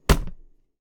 crumblerSmash.ogg